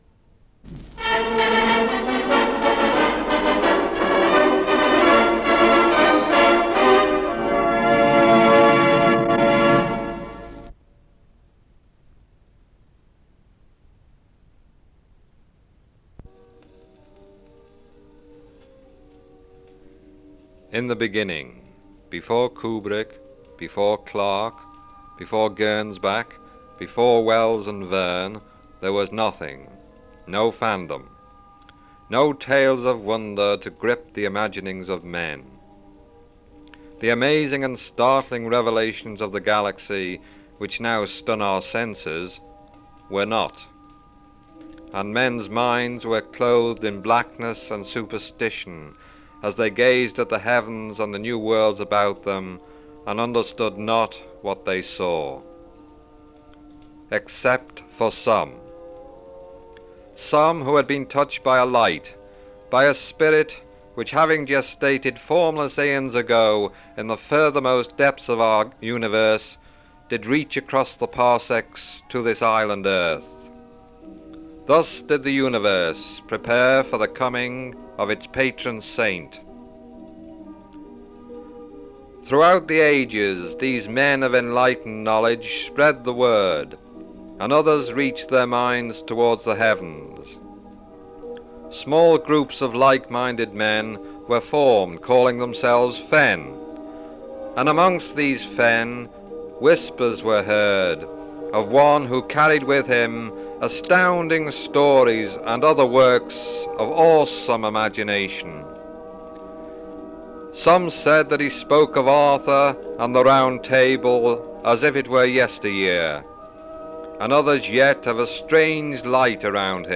There are also two short musical cues at the end that would inserted at appropriate points.
STFintro.mp3